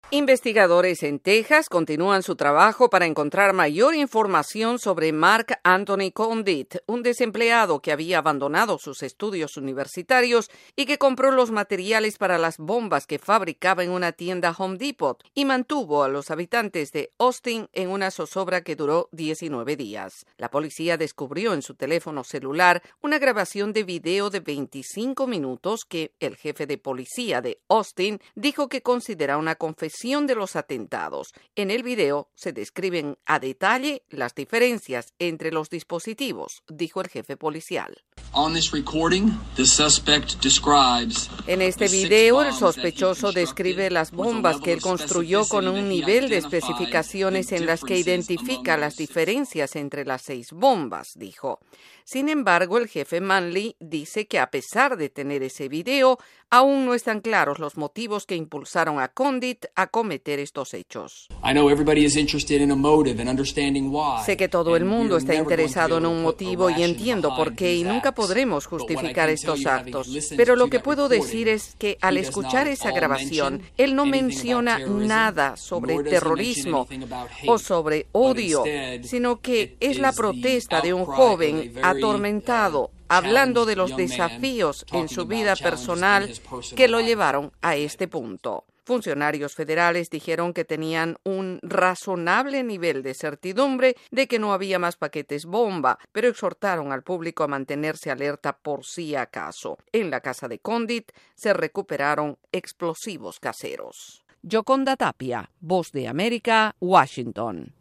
Investigadores en Texas continúan develando detalles de los ataques con bombas y sobre el sospechoso que murió al explotar uno de sus dispositivos. Desde la Voz de América en Washington DC informa